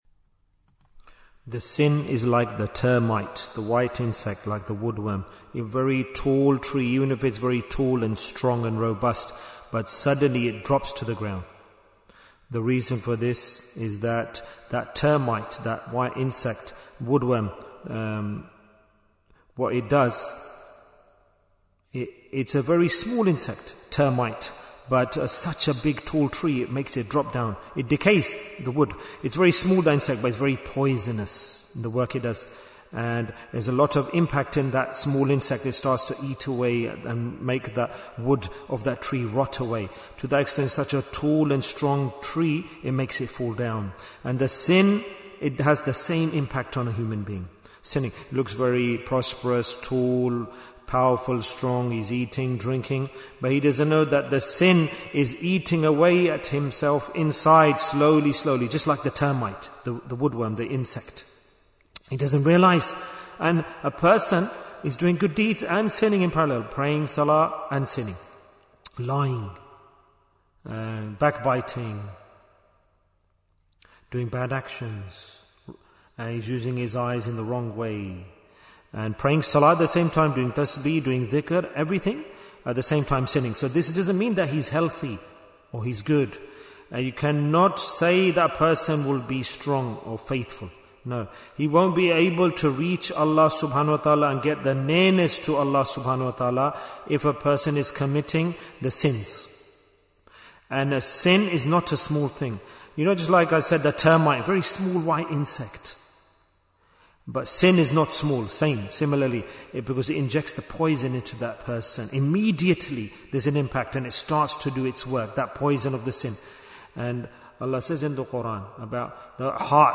- Part 15 Bayan, 48 minutes 28th January, 2026 Click for Urdu Download Audio Comments Why is Tazkiyyah Important? - Part 15 Sins are not insignificant.